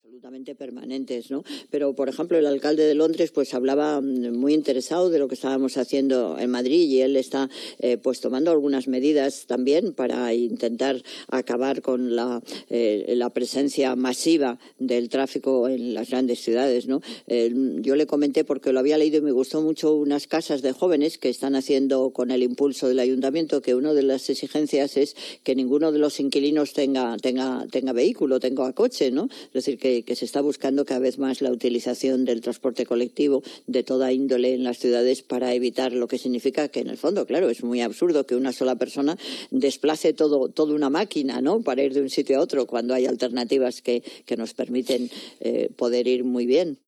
Llaman mucho la atención unas palabras que la alcaldesa Carmena deslizó, durante una entrevista en Onda Cero con Julia Otero, el día del gran estreno.